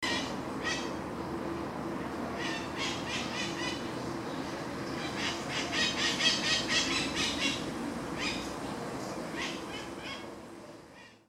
White-eyed Parakeet (Psittacara leucophthalmus)
Life Stage: Adult
Location or protected area: Ramos Mejía
Condition: Wild
Certainty: Recorded vocal